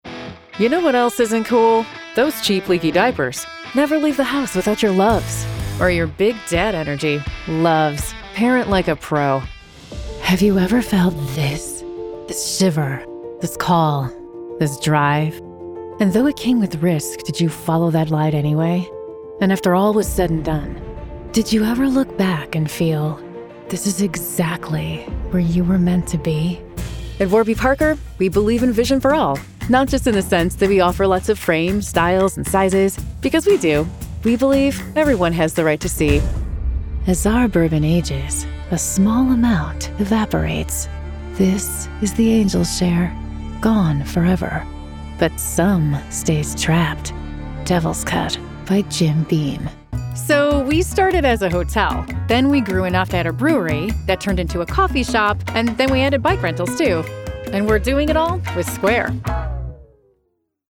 Commercial
English - USA and Canada